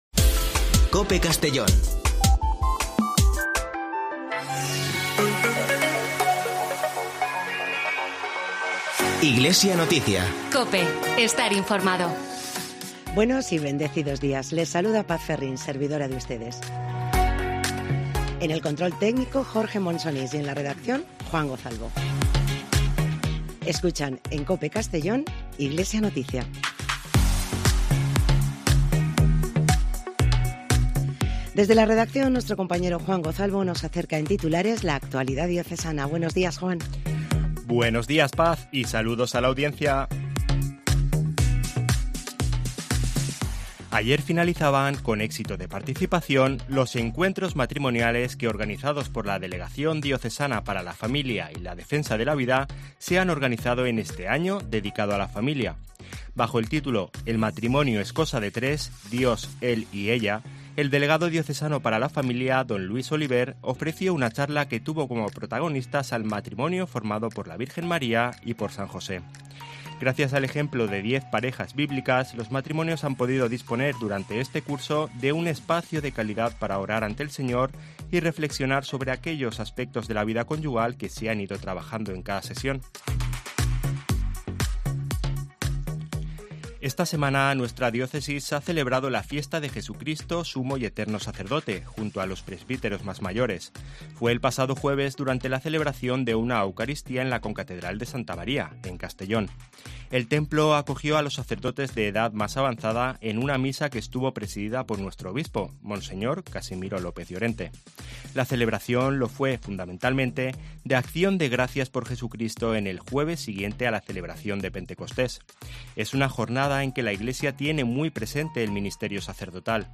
Espacio informativo